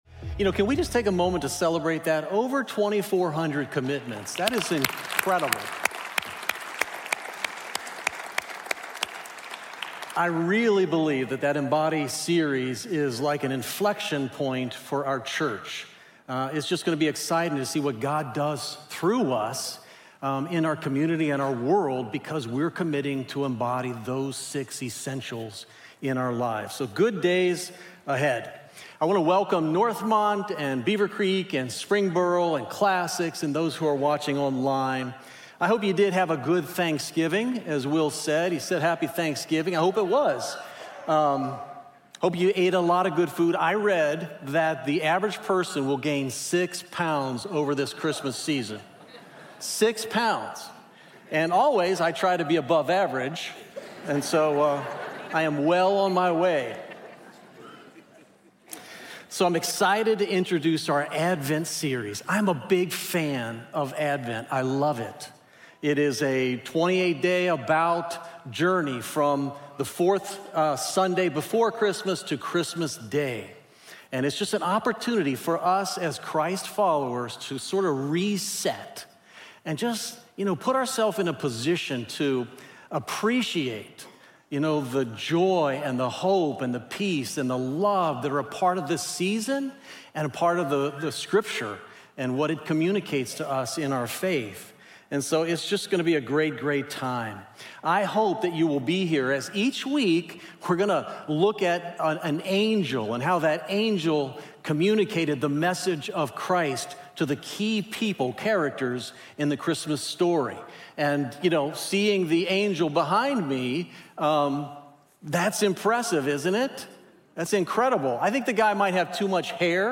Unto-Us_The-Miracle-Begins_SERMON.mp3